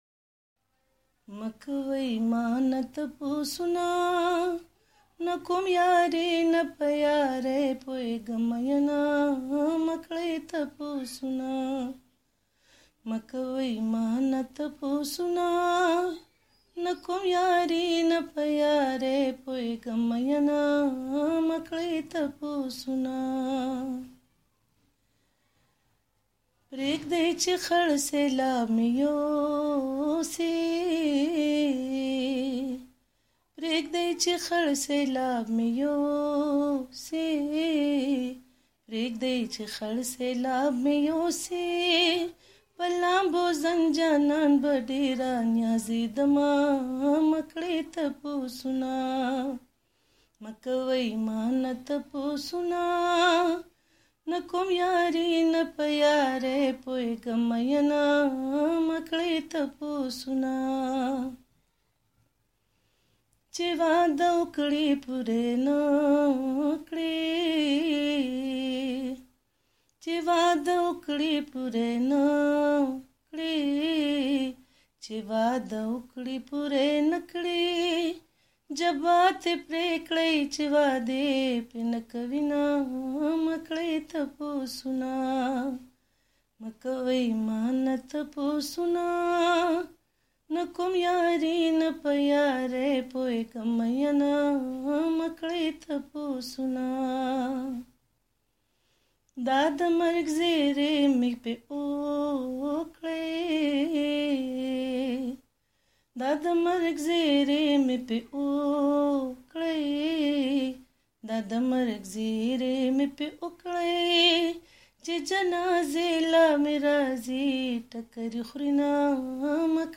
غږ یې اوس د مخکې نه نور هم ډیر پوخ اؤ سُریله شوی دی.